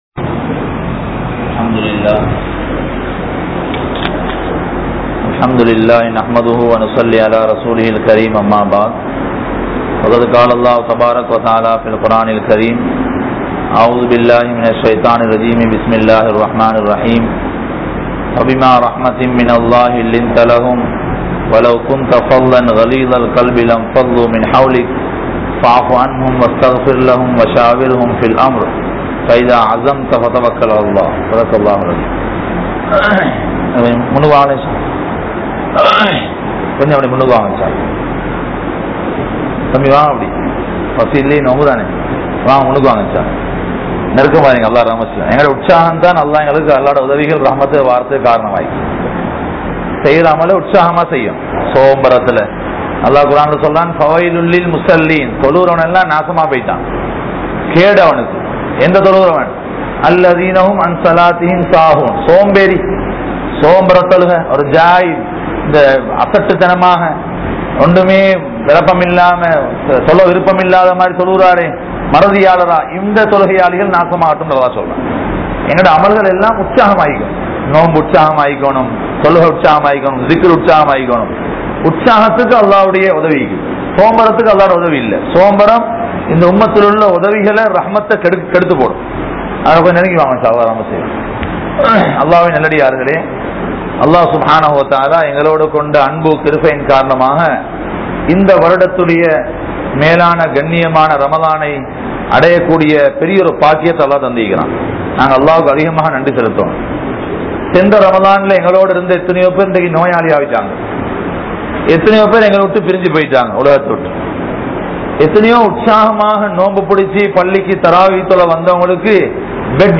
Noanpum Indraya Muslimkalum | Audio Bayans | All Ceylon Muslim Youth Community | Addalaichenai